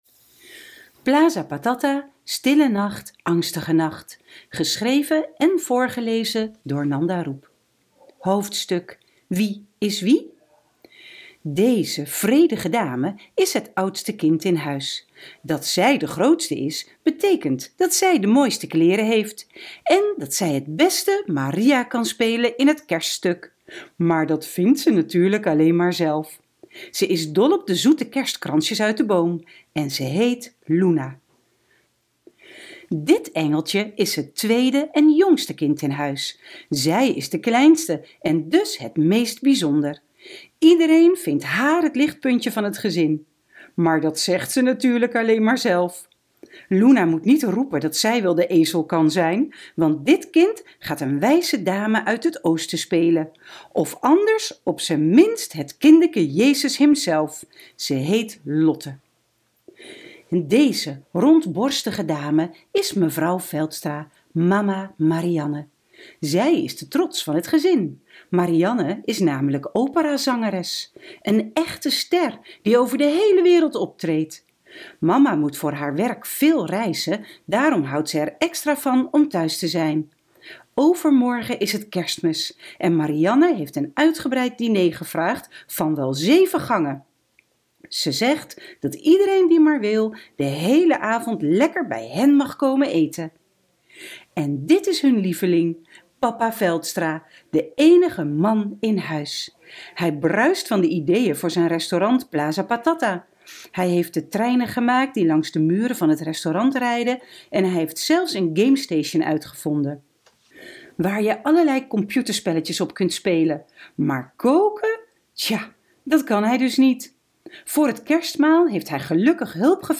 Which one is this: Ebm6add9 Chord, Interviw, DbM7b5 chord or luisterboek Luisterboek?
luisterboek Luisterboek